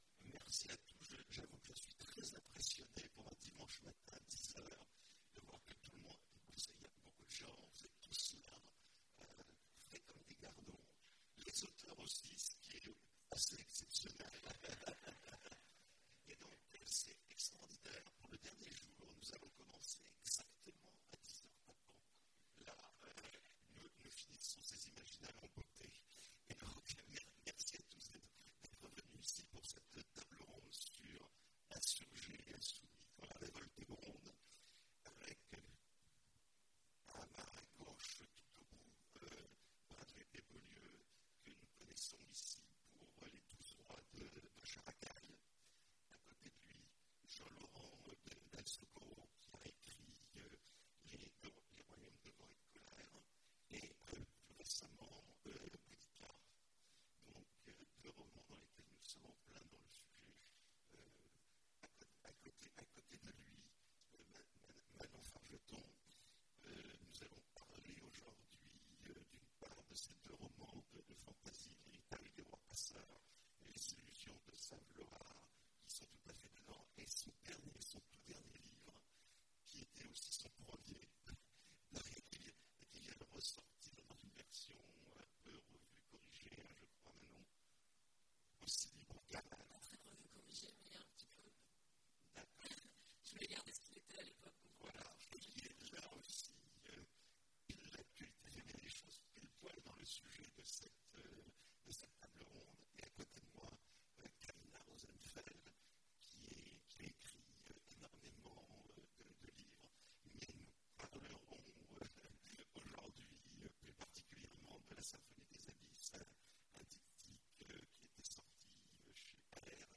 Imaginales 2017 : Conférence Insurgés et insoumis : Quand la révolte gronde !